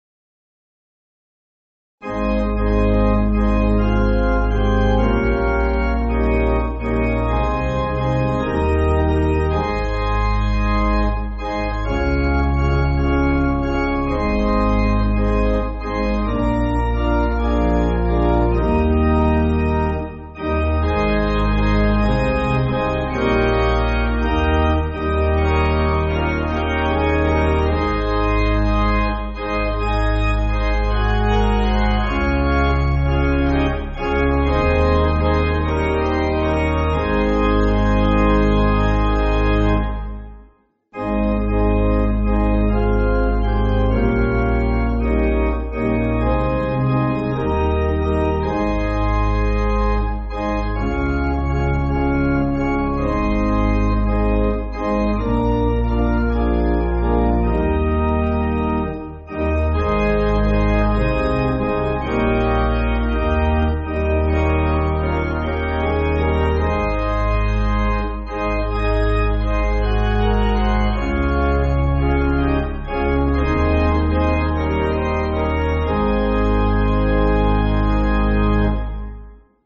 Organ
(CM)   3/Ab